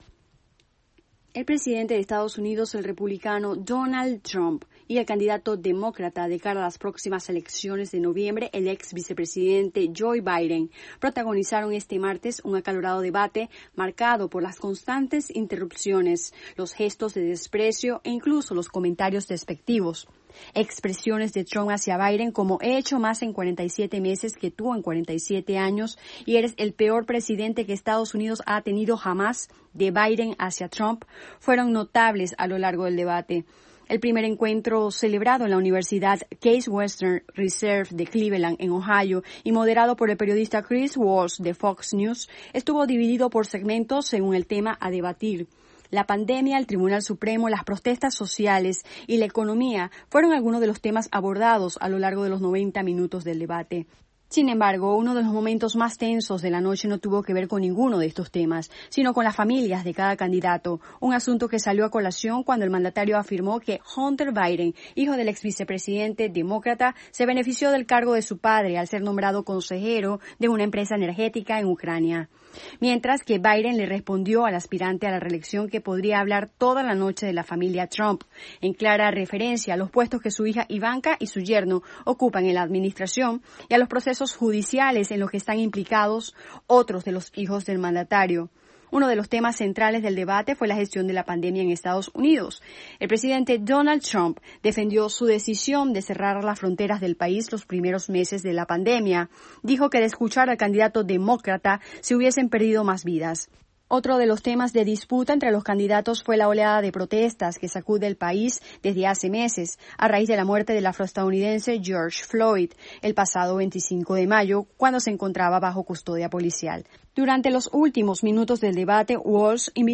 desde Washington DC.